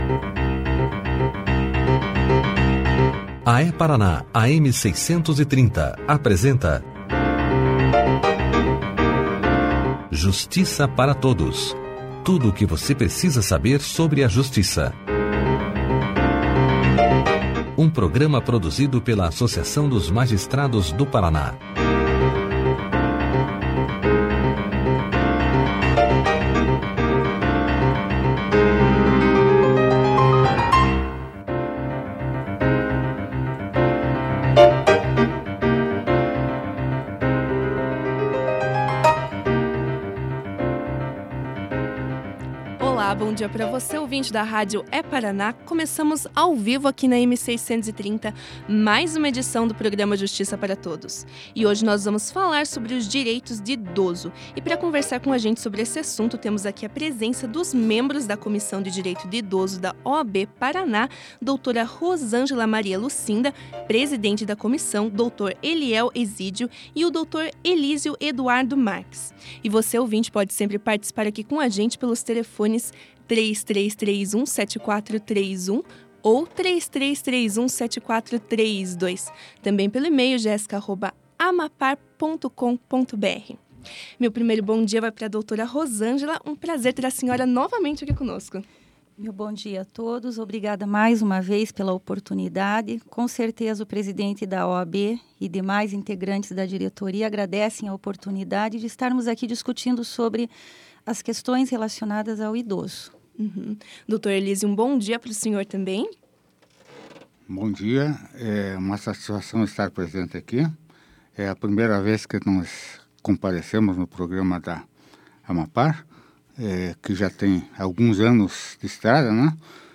Clique aqui e ouça a entrevista dos membros da Comissão dos Direitos dos Idosos da OAB-PR sobre Direito do Idoso e a Delegacia do Idoso no Paraná na íntegra.